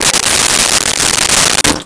Sink Fill 05
Sink Fill 05.wav